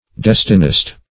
Destinist \Des"ti*nist\